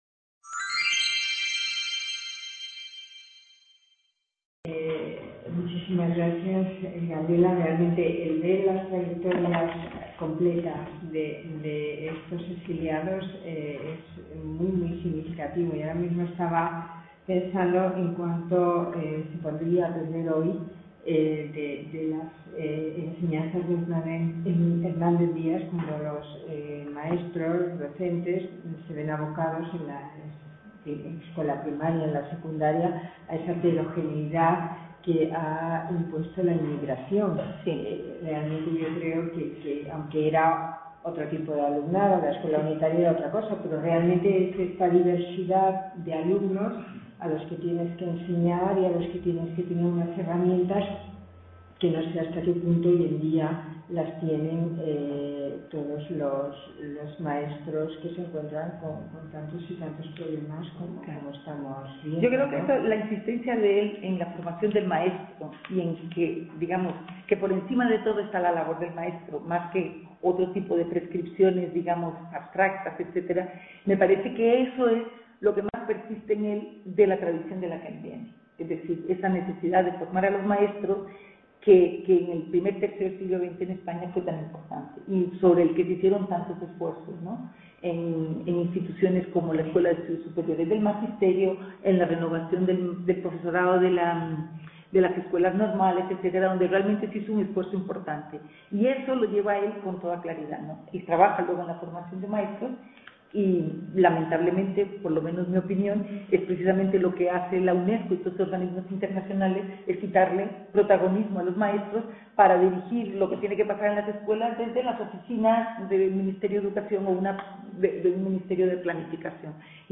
Debate.